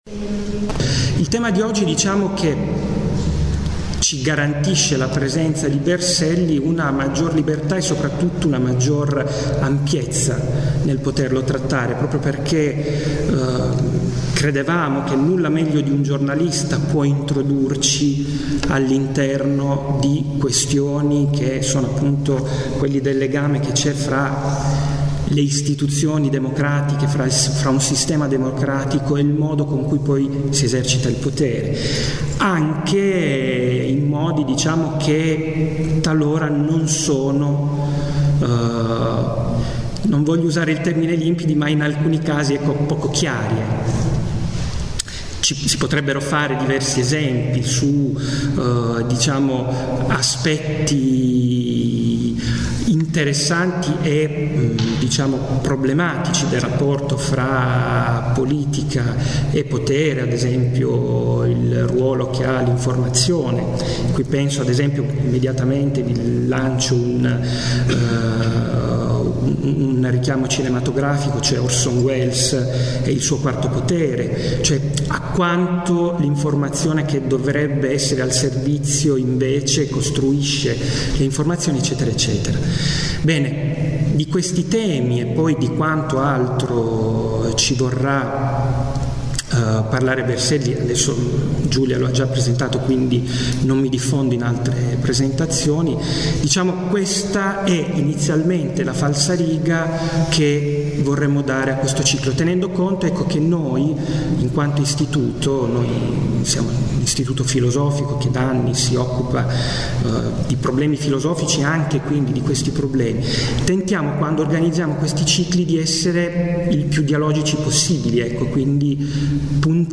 tratte da Festival Storia Saluzzo e Savgliano 2007